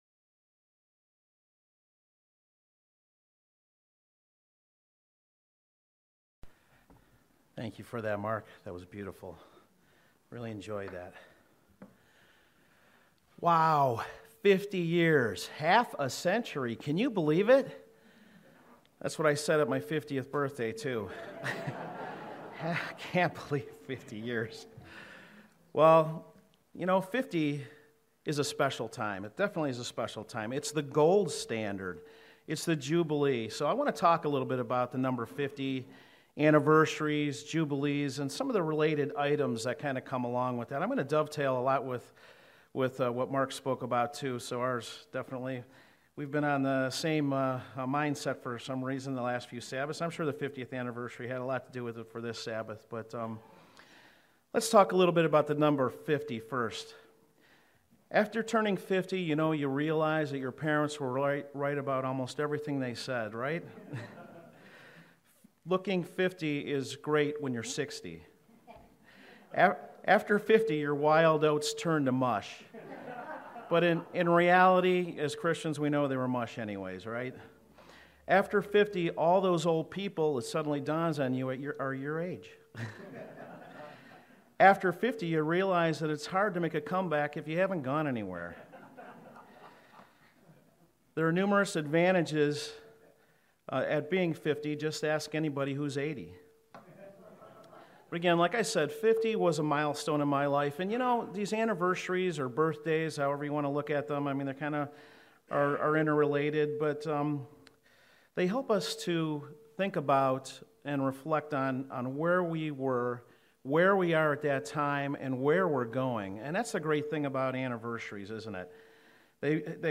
Fifty is a number often mentioned in scripture as it has biblical significance. In this Sermon we celebrate and discuss the 50th anniversary of the Cleveland congregation.